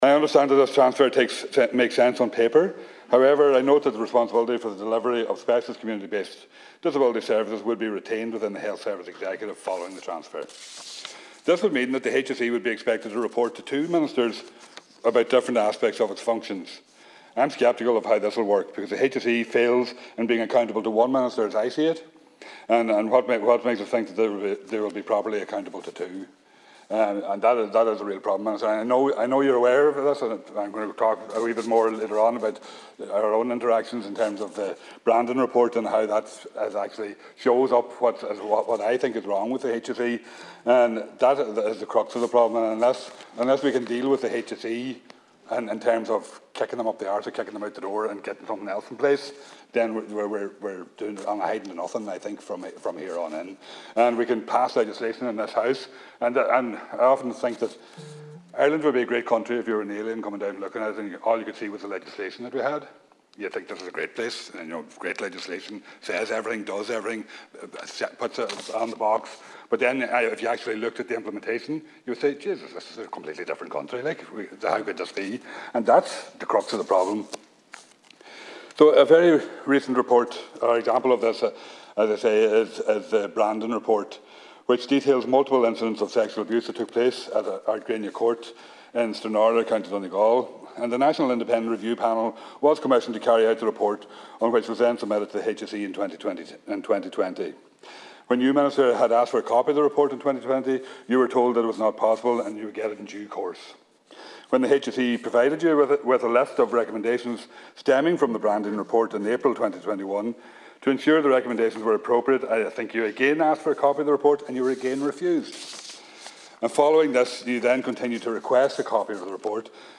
Deputy Thomas Pringle was speaking as part of statements on the Health (Miscellaneous Provisions) Bill 2022 which would pave the way for a transfer of some responsibilities to a different Minister.